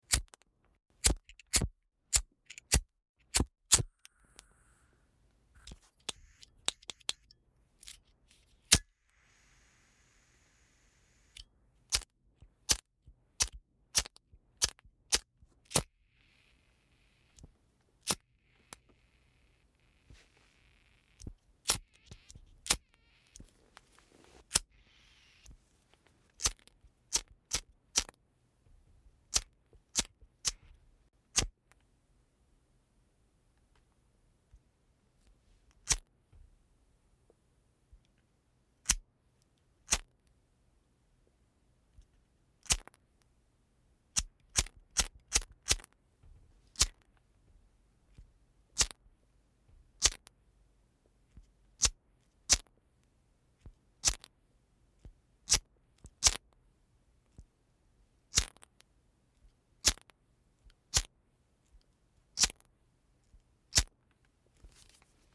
Звуки марихуаны
Звук курения марихуаны через трубку